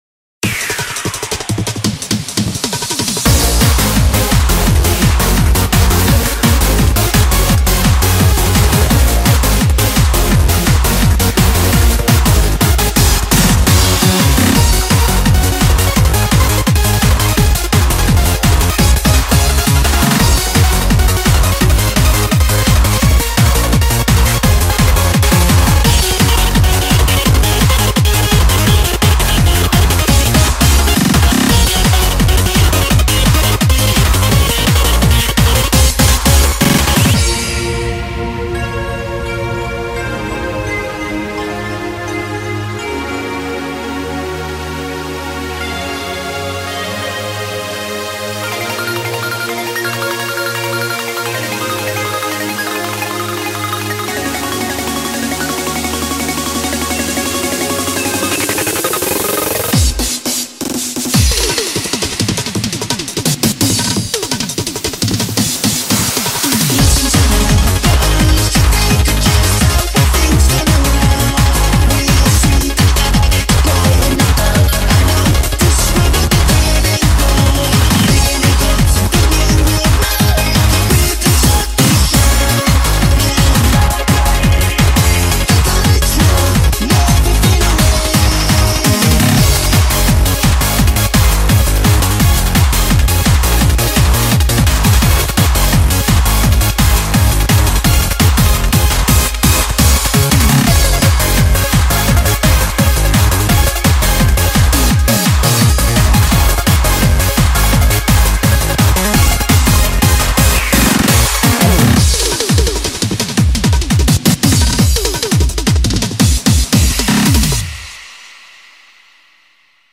BPM170
Audio QualityPerfect (Low Quality)